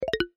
Pause Button.wav